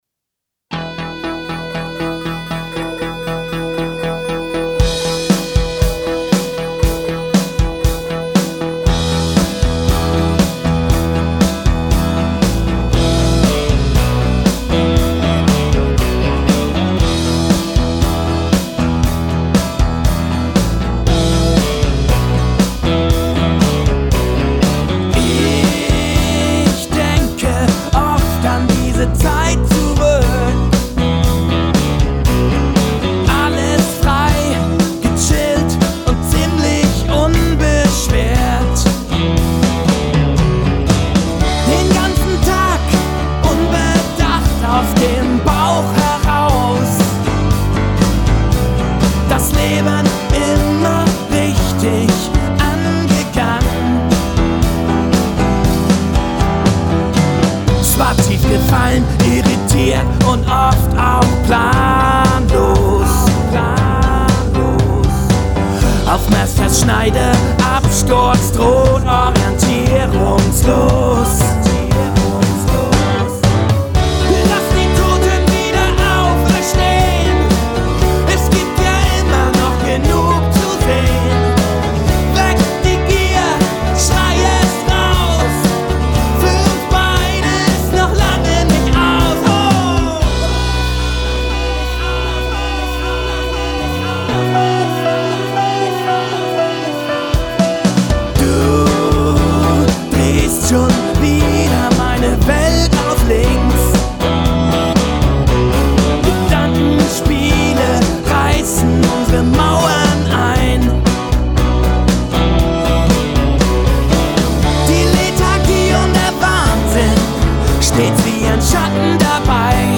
Geigen setzen ein